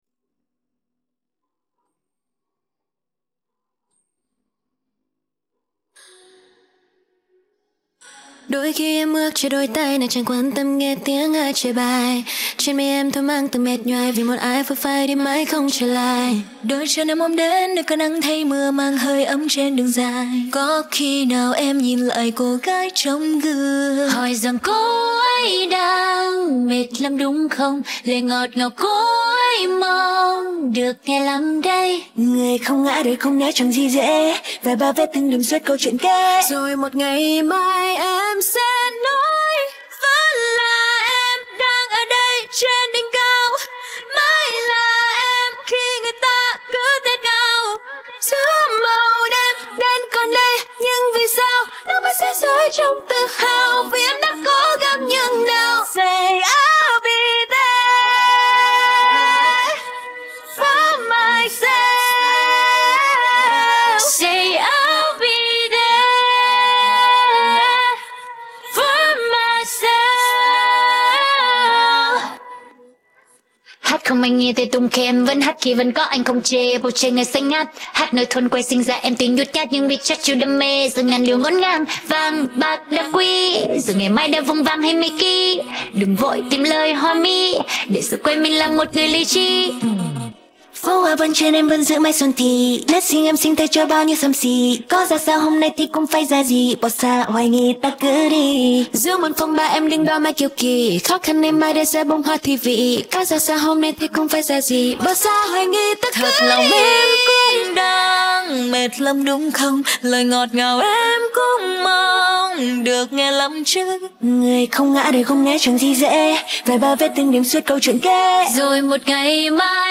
गायन भाग